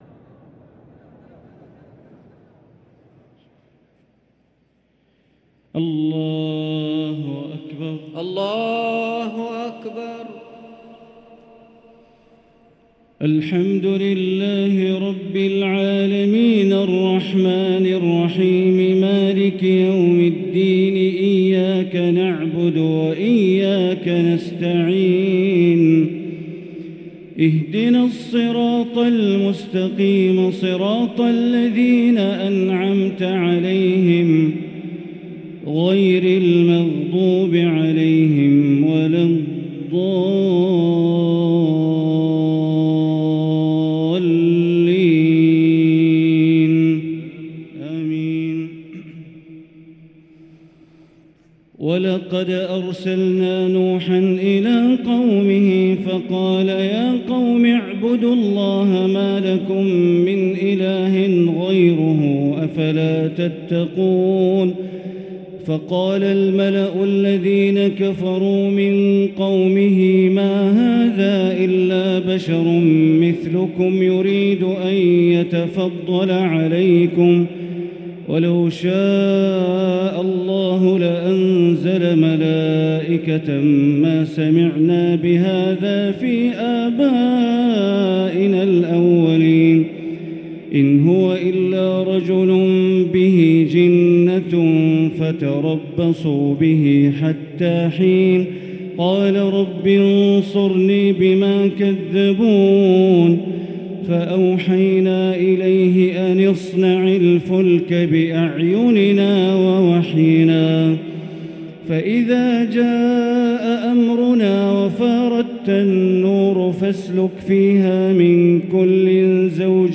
تراويح ليلة 22 رمضان 1444هـ من سورة المؤمنون (23-118) | Taraweeh 22th night Ramadan 1444H Surah Al-Muminoon > تراويح الحرم المكي عام 1444 🕋 > التراويح - تلاوات الحرمين